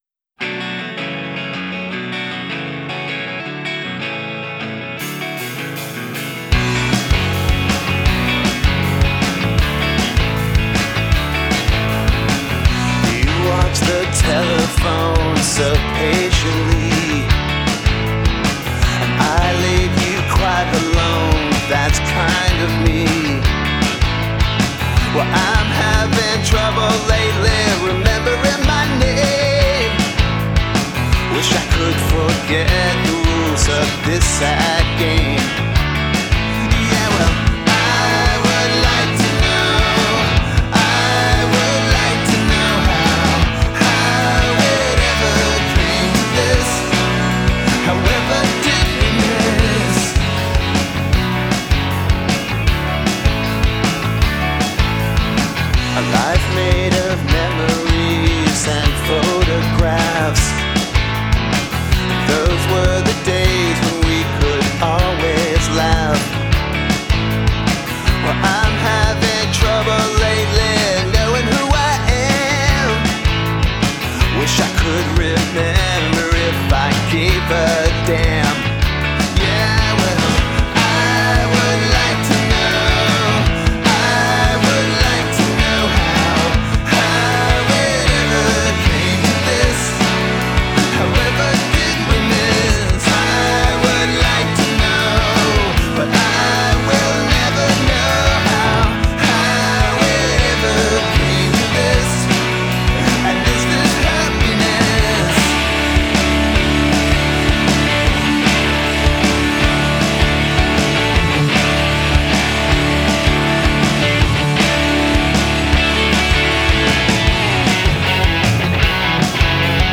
Tempo 156 BPM
Key A